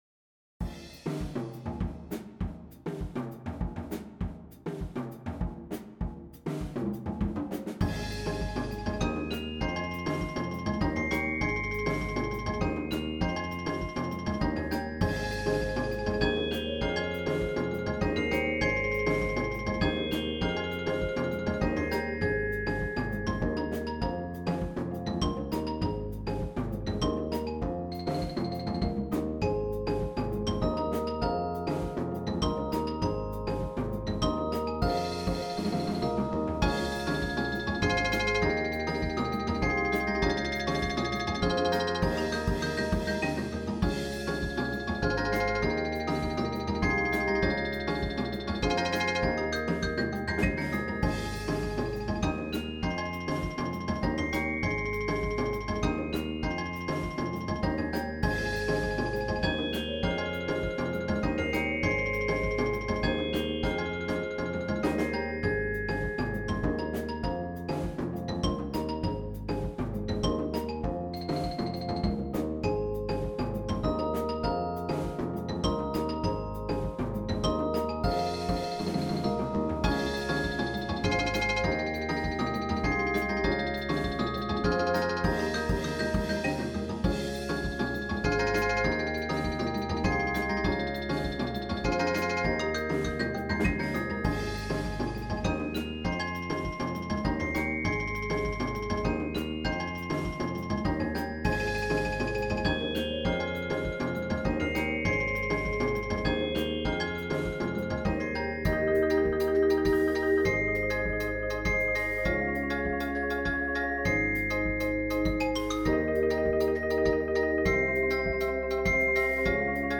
Mallet-Steelband Muziek
Bells Vibrafoon Xylofoon Marimba Bass gitaar Conga's Drumset